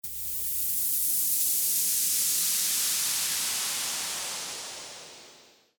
FX-252-WIPE-WHOOSH
FX-252-WIPE-WHOOSH.mp3